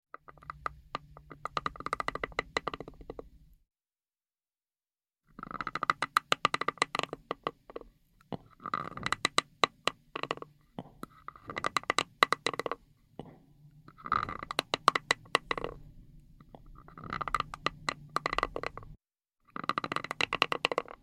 На этой странице собраны различные звуки скрежета зубами – от непроизвольного бруксизма до намеренного скрипа.
Звук трения верхней и нижней челюсти